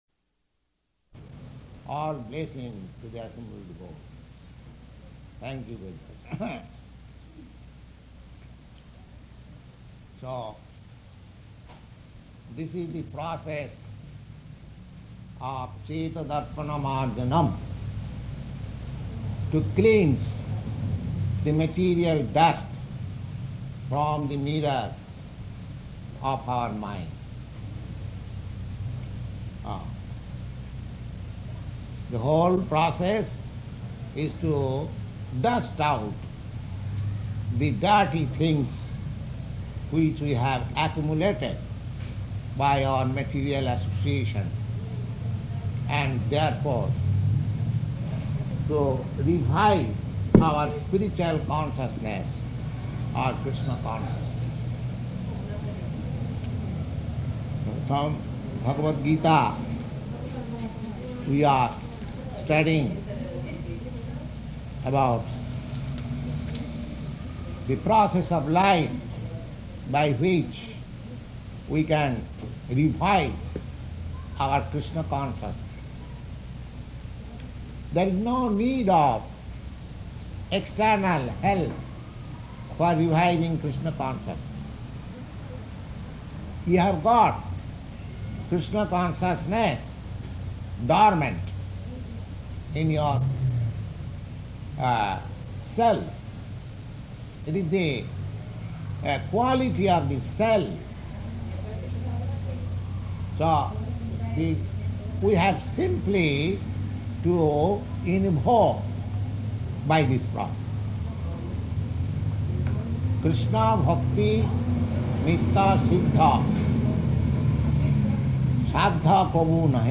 Bhagavad-gītā 4.19 --:-- --:-- Type: Bhagavad-gita Dated: August 3rd 1966 Location: New York Audio file: 660803BG-NEW_YORK.mp3 Prabhupāda: All blessings to the assembled devotees.